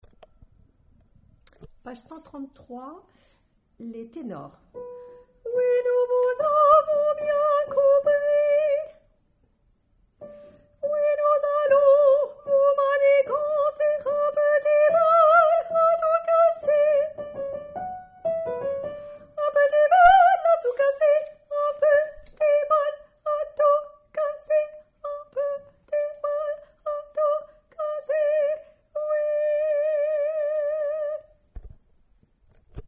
Tenor
p133_Tenor.mp3